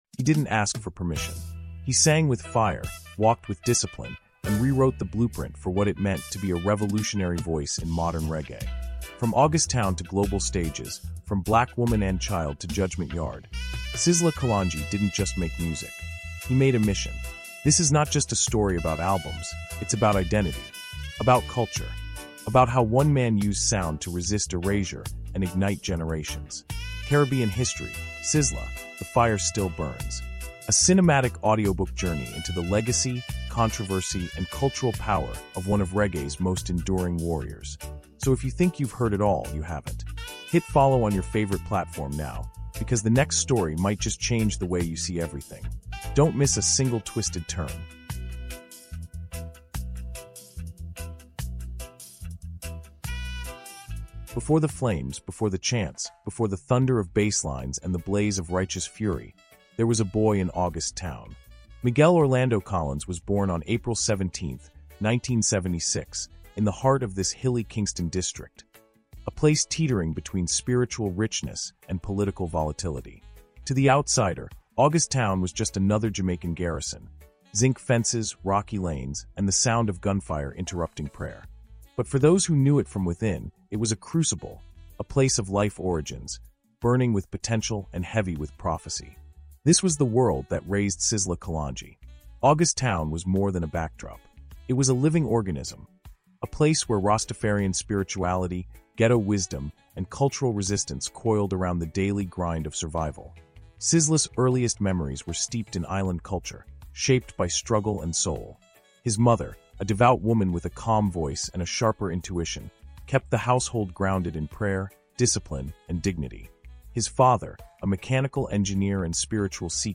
CARIBBEAN HISTORY: Sizzla — The Fire Still Burns is a powerful, emotionally immersive audiobook documentary exploring the extraordinary life, music, and cultural legacy of Miguel Orlando Collins, better known as Sizzla Kalonji. Told in cinematic chapters and narrated with spiritual depth, this is the untold story of a militant voice born in August Town, raised by Bobo Ashanti order, and forged in the fires of reggae and dancehall.